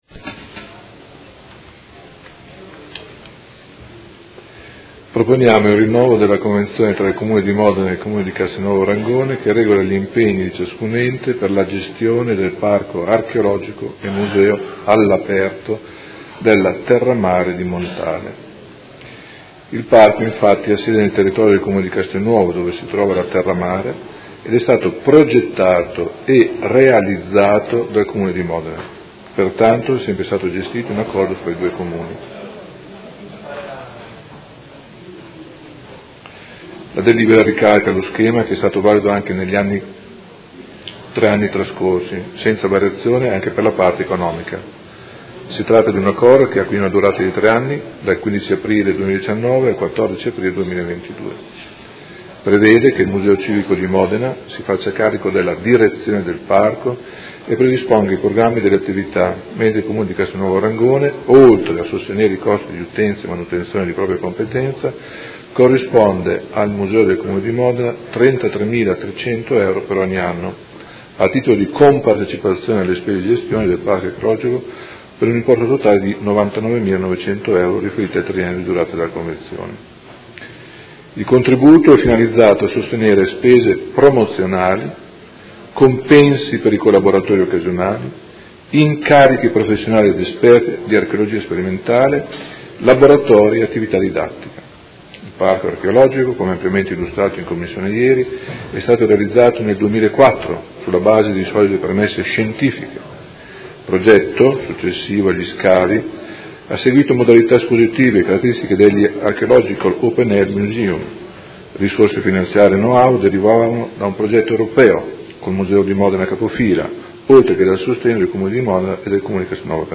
Gianpietro Cavazza — Sito Audio Consiglio Comunale